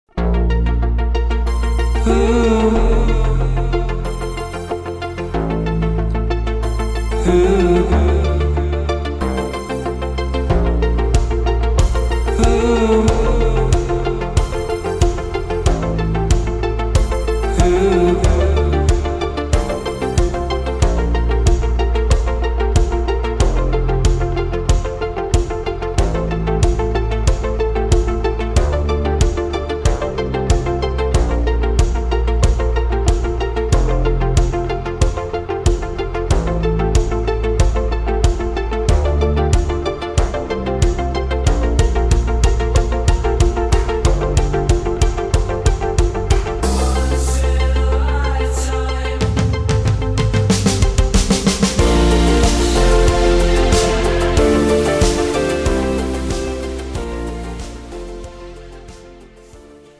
음정 원키
장르 pop 구분